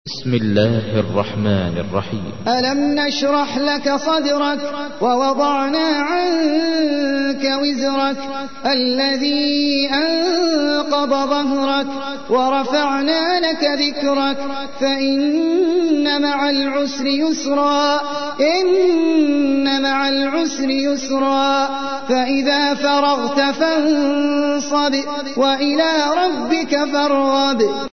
تحميل : 94. سورة الشرح / القارئ احمد العجمي / القرآن الكريم / موقع يا حسين